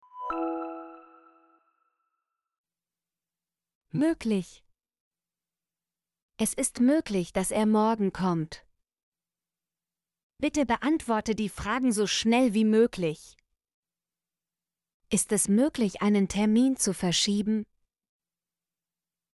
möglich - Example Sentences & Pronunciation, German Frequency List